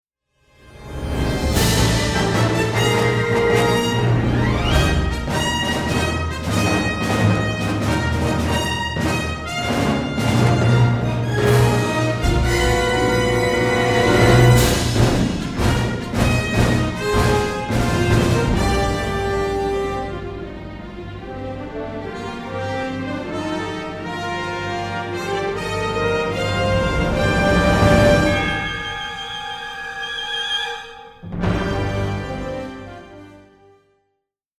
charming orchestral score